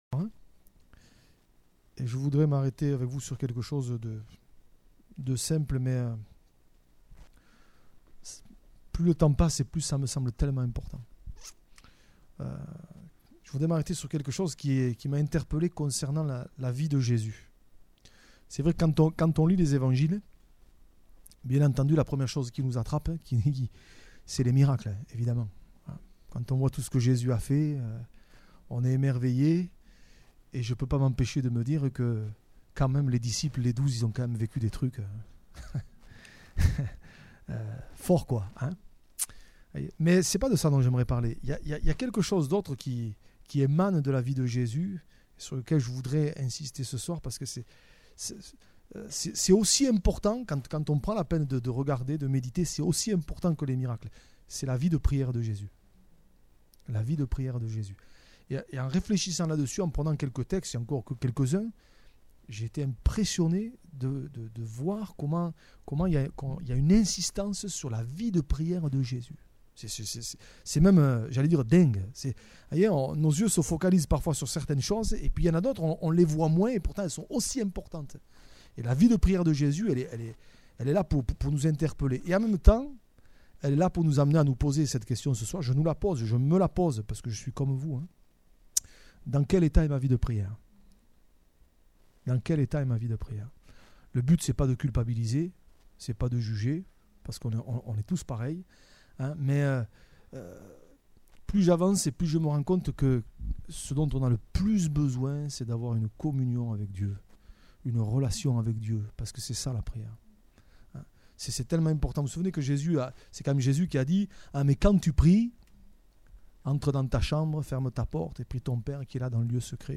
Type De Service: Etude Biblique